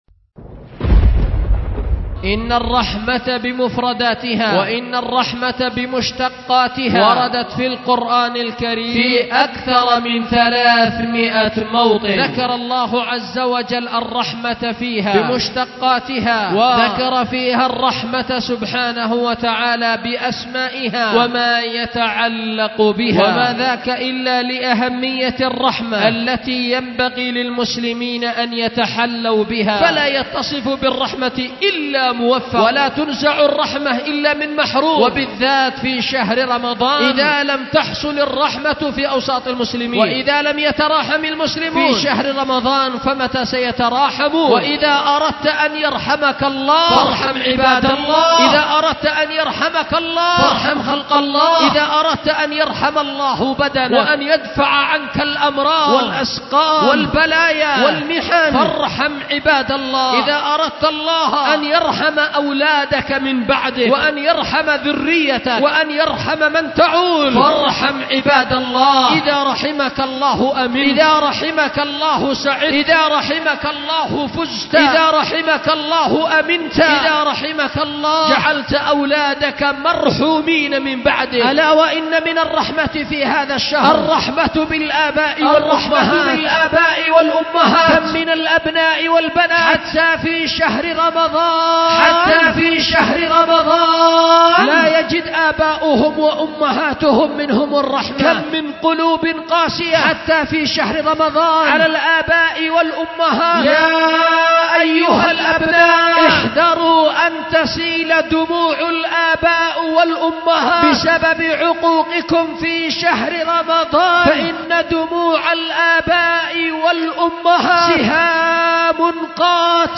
خطبة
أُلقيت بدار الحديث للعلوم الشرعية بمسجد ذي النورين ـ اليمن ـ ذمار